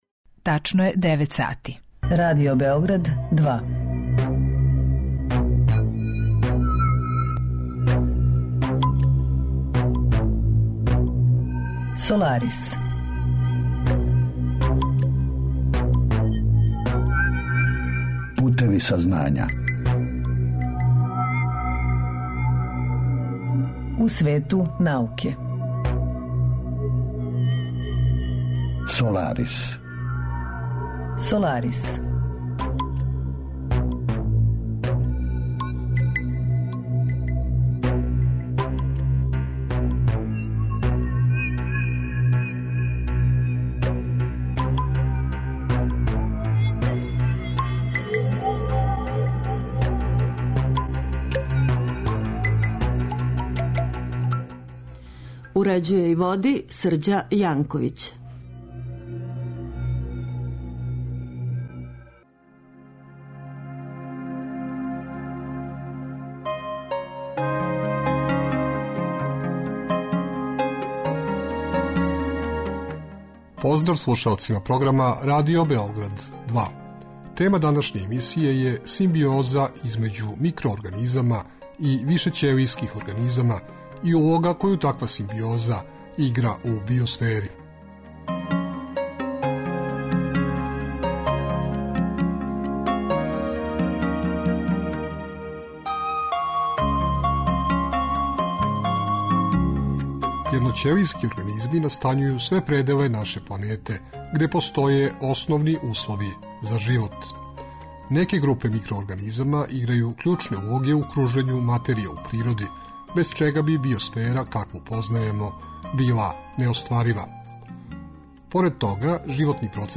Разговор је први пут емитован 11. фебруара 2015.